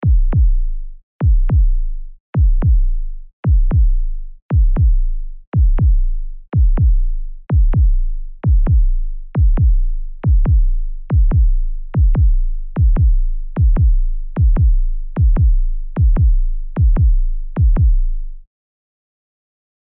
دانلود آهنگ ریتم قلب از افکت صوتی انسان و موجودات زنده
دانلود صدای ریتم قلب از ساعد نیوز با لینک مستقیم و کیفیت بالا
جلوه های صوتی